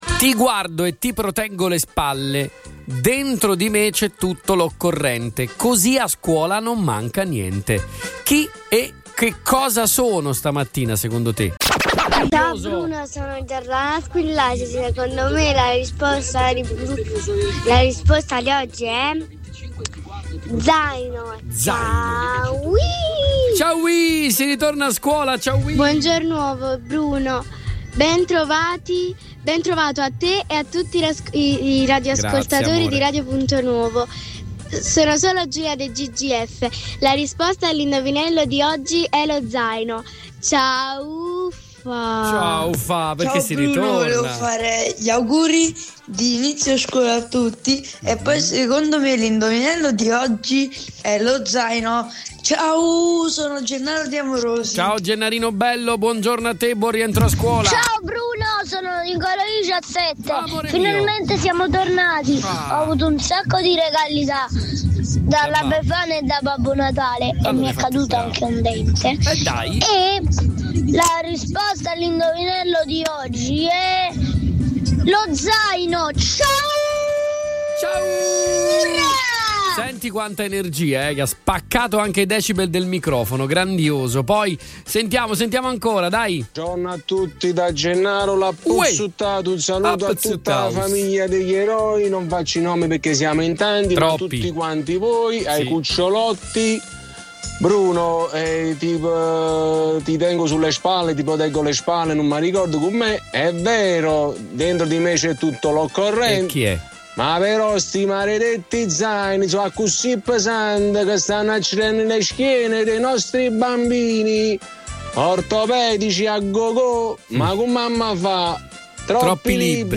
PODCAST – LE RISPOSTE DEGLI ASCOLTATORI